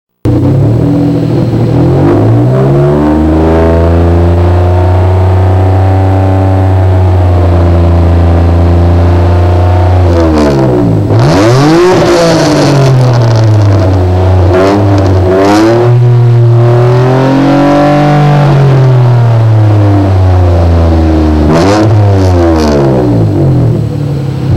Idle clip with Performance-Plus y-pipe